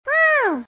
yoshi_Ledge_Gra.ogg